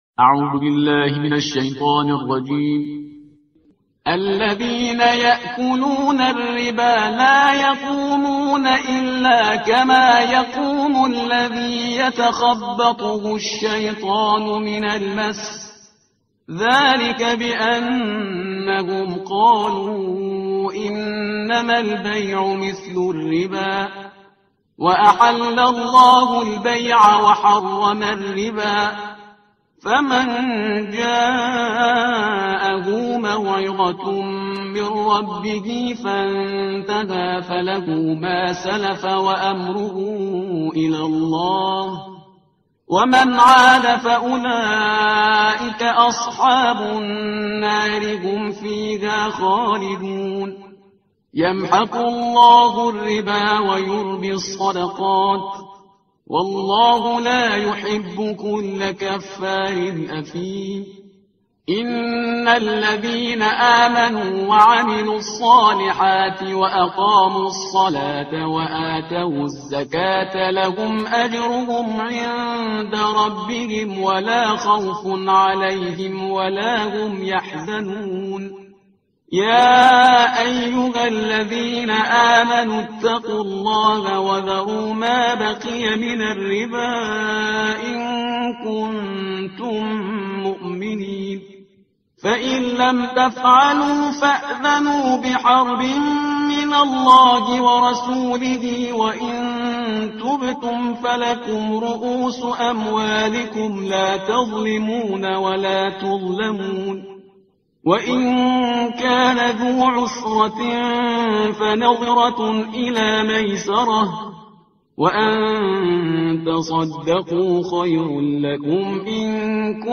ترتیل صفحه 47 قرآن با صدای شهریار پرهیزگار